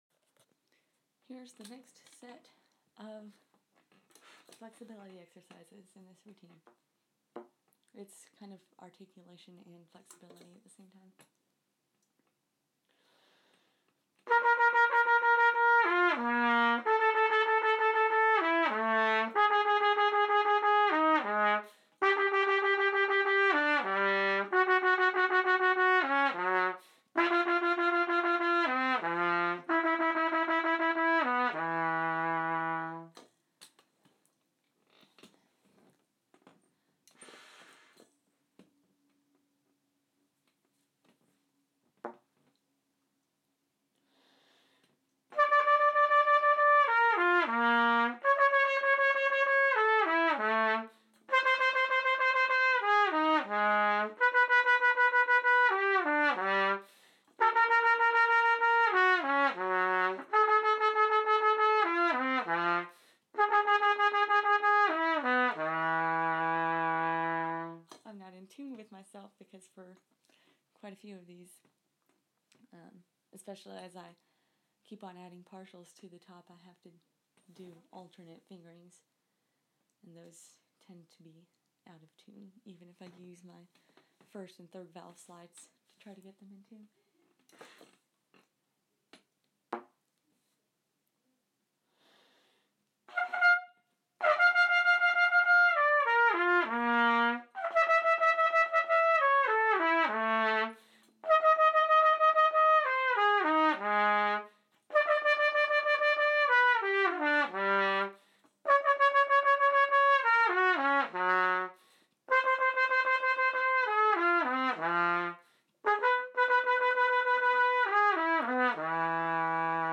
trumpet flexibility exercises, part two